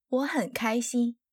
Wǒ hěn kāixīn
ウォ ヘン カイシン